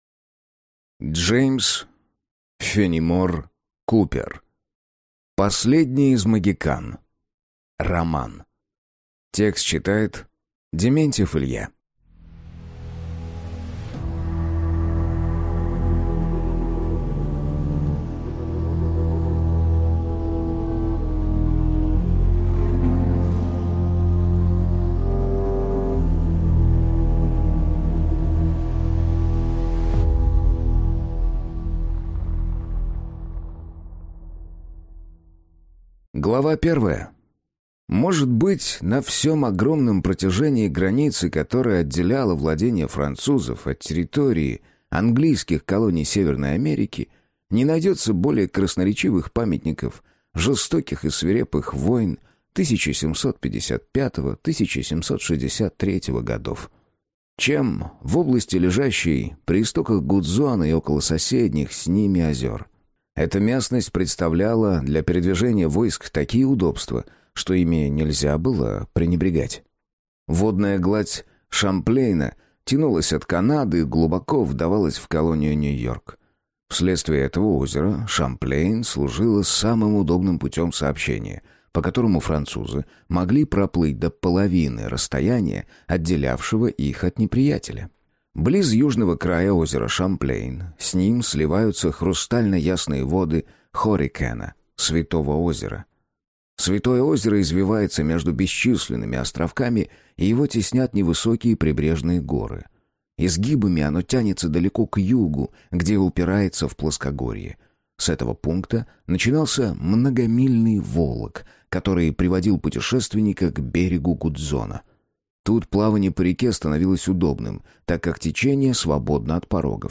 Аудиокнига Последний из могикан | Библиотека аудиокниг